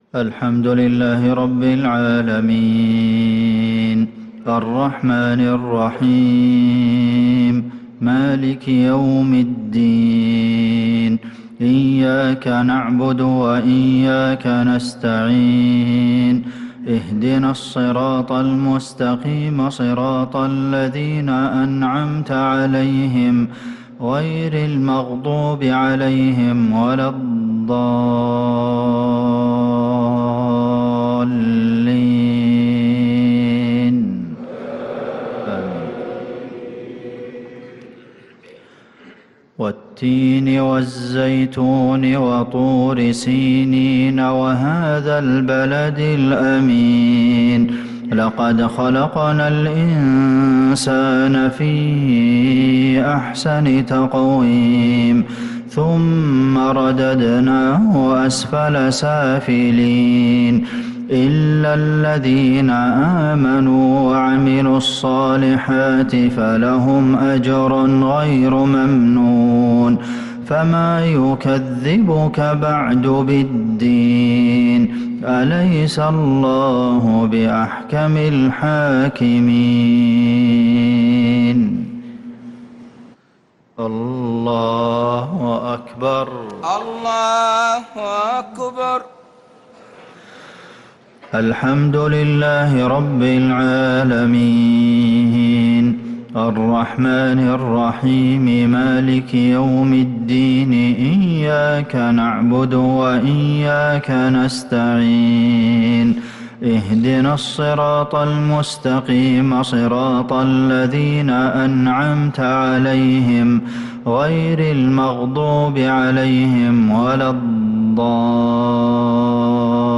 صلاة المغرب للقارئ عبدالمحسن القاسم 2 شوال 1445 هـ